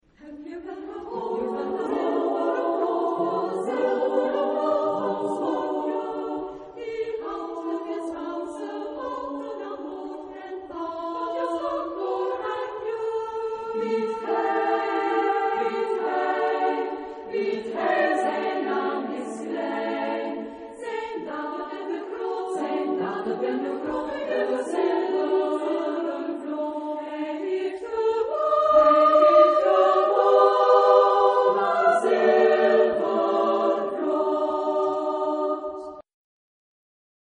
Genre-Style-Forme : Profane ; Folklore ; Chanson de marins
Type de choeur : SSAATTBB  (8 voix mixtes )
Tonalité : ré majeur